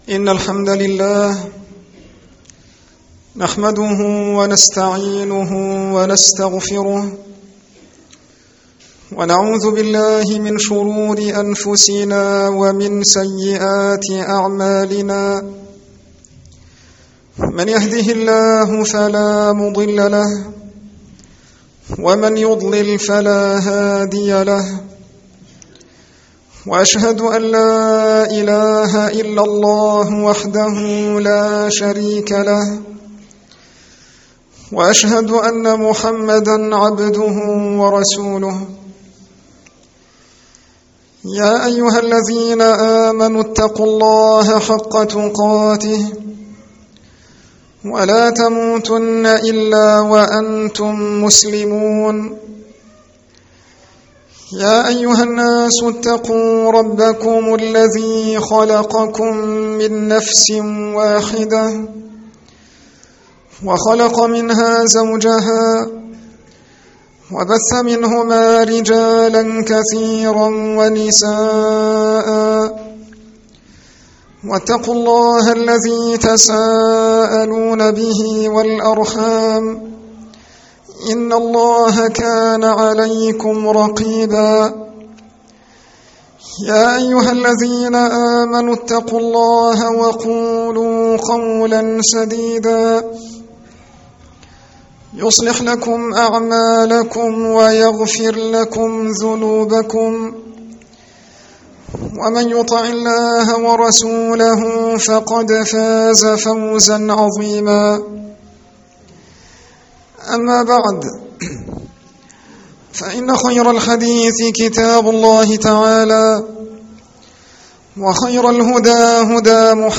خطب عامة